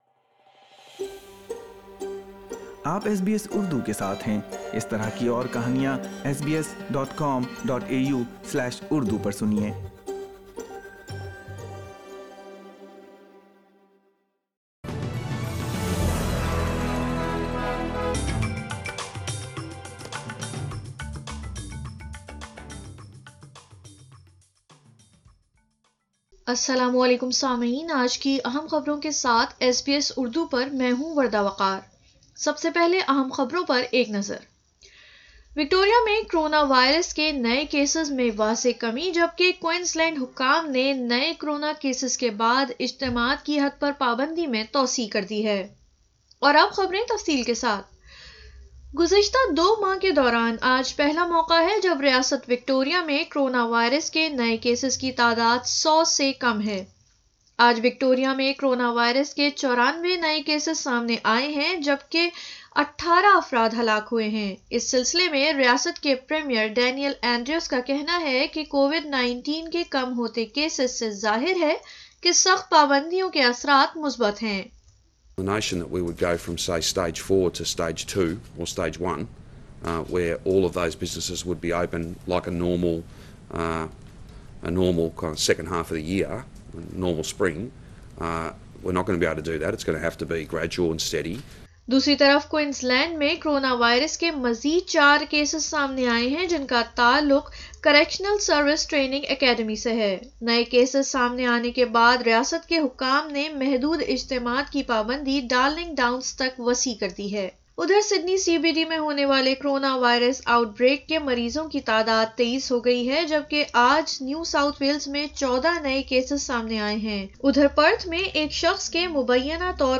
آردو خبریں 29 اگست 2020